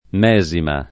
[ m $@ ima ]